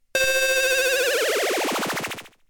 不思議系効果音です。
ビーワワーン・・・